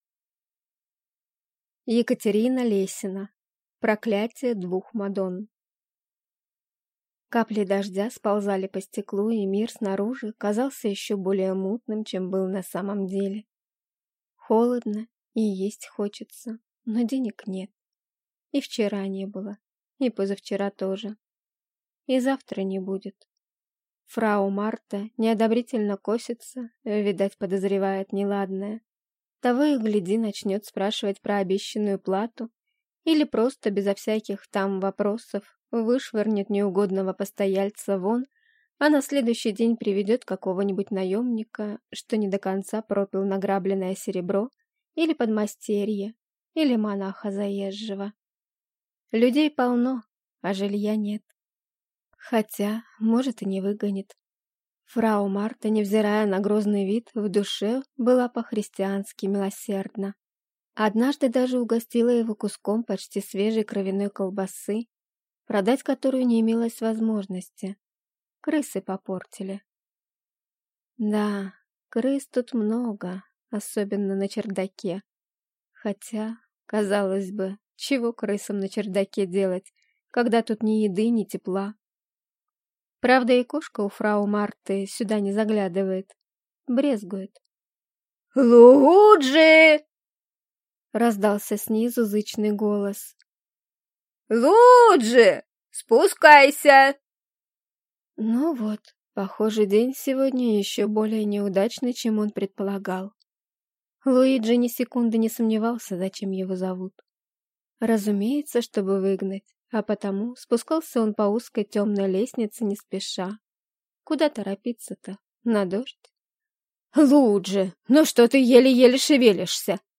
Аудиокнига Проклятие двух Мадонн | Библиотека аудиокниг